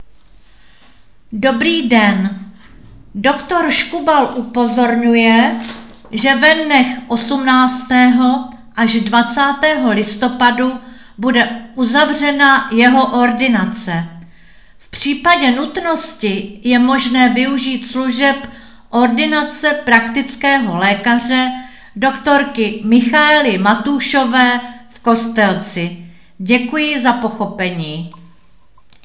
Hlášení místního rozhlasu
Hlášení ze dne 13.11.2025